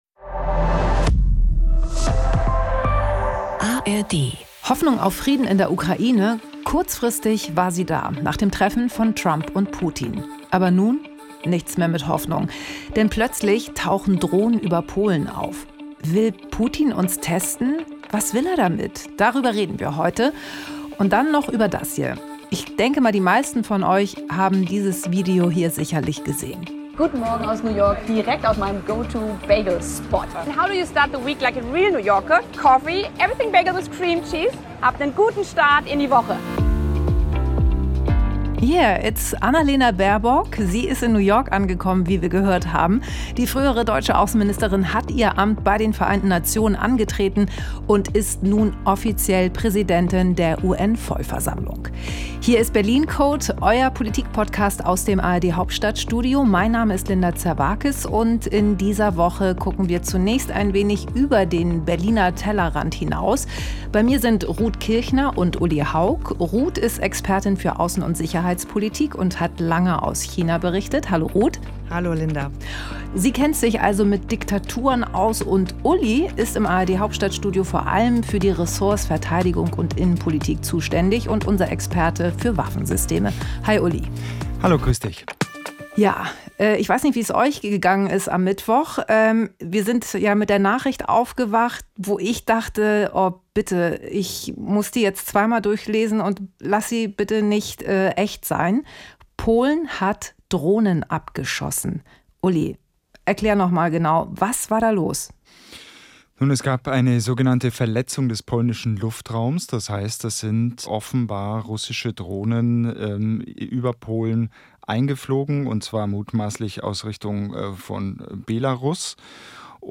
Außerdem sprechen die Drei über den Start von Ex-Außenministerin Baerbock in New York bei der UN.